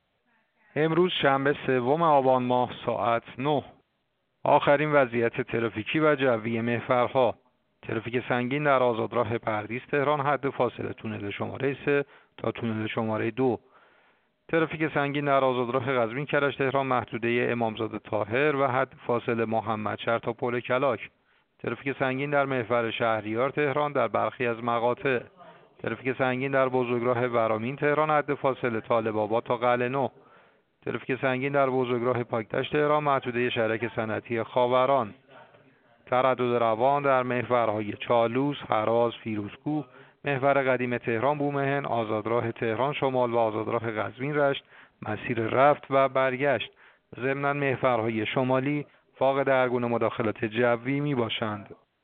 گزارش رادیو اینترنتی از آخرین وضعیت ترافیکی جاده‌ها ساعت ۹ سوم آبان؛